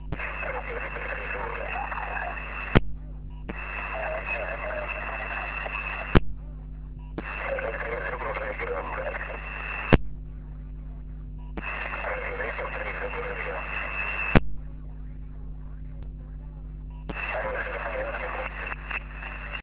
Meteor Scatter
But the activity was great, some pile-ups, QRM as usually, so I worked about 100 QSOs, all random SSB.
Used rig: TCVR R2CW, PA 500W, ant: 4x9el (North-East), 4x4el (West) and 4x4el (South).